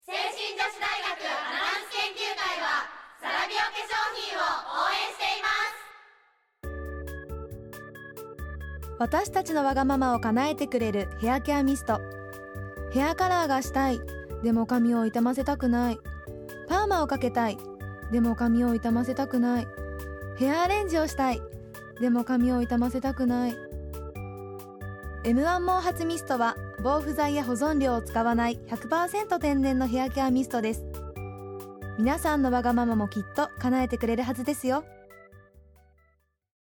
どれも清々しいお声で、皆さんの笑顔が浮かぶステキなＣＭです！
聖心女子大学アナウンス研究会による「ラジオCM」